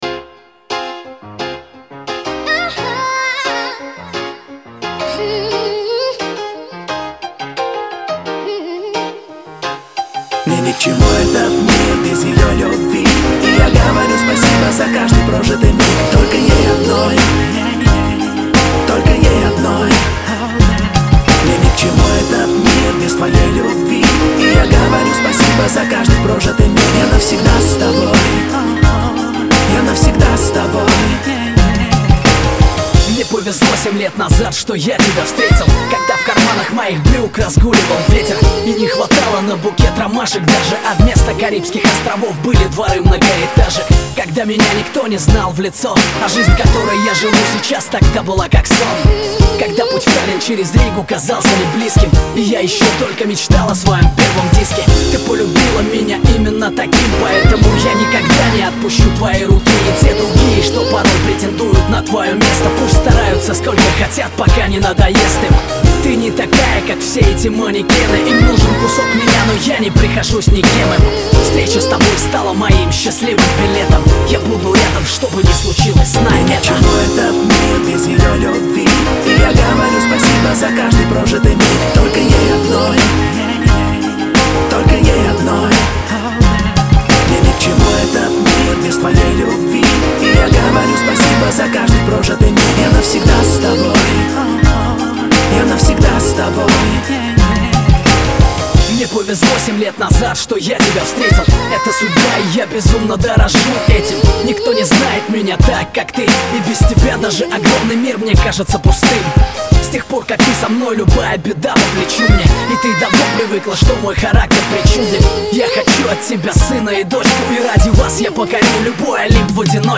Жанр: Русский рэп, размер 7.79 Mb.